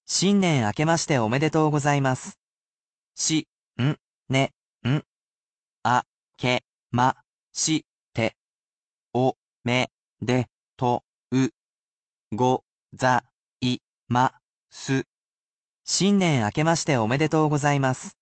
Each week, I present a word of phrase in Japanese, reading it aloud, and sounding it out.